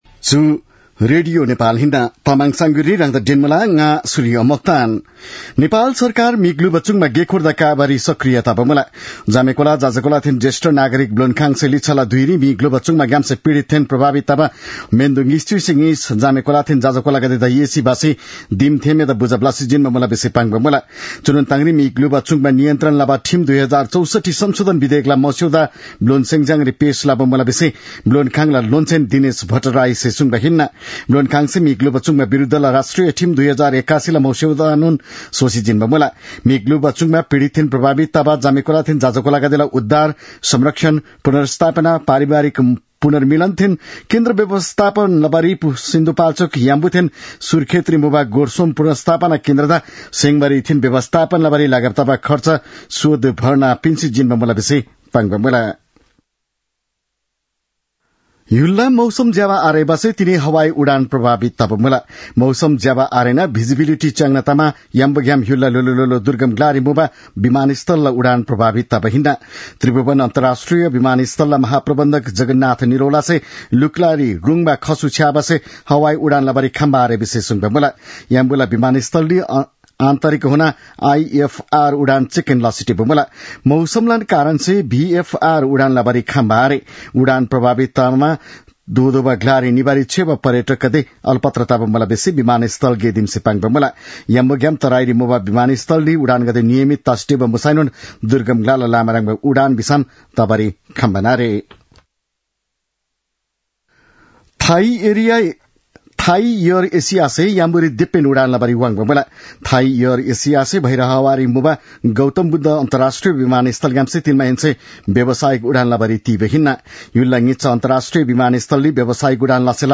तामाङ भाषाको समाचार : २० कार्तिक , २०८१